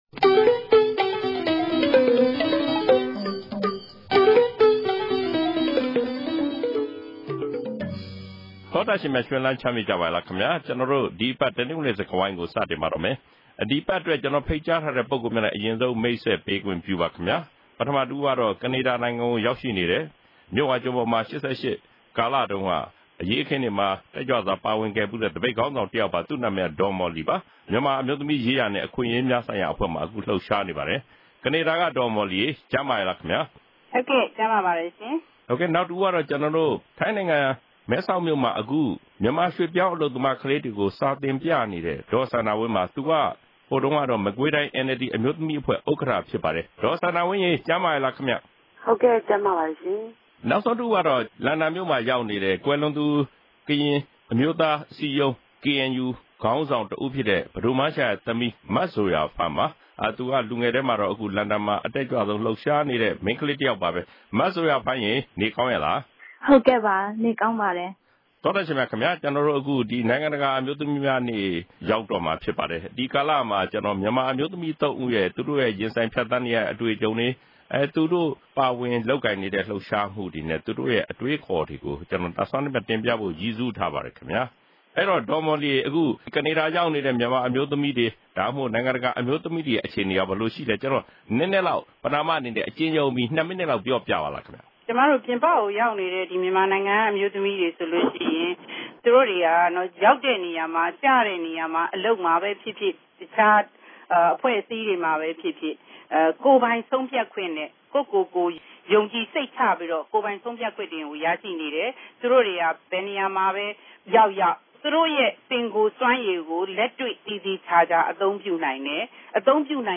စကားဝိုင်း